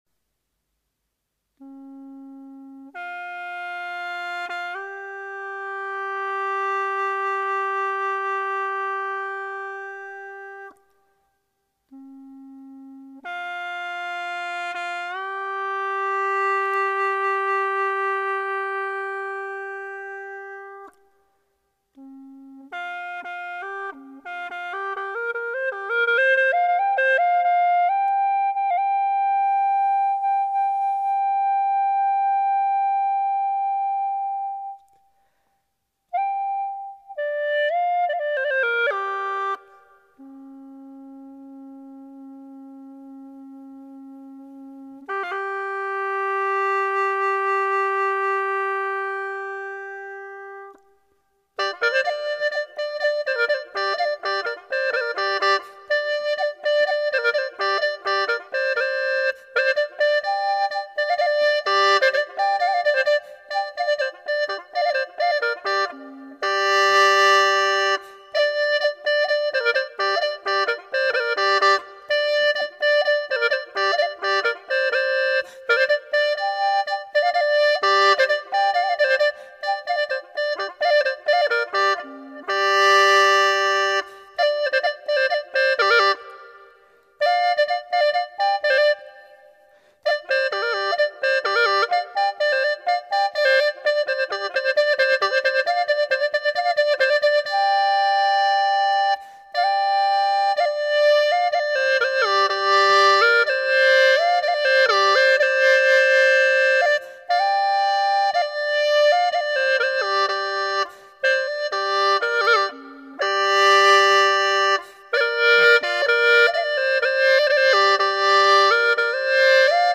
调式 : 降B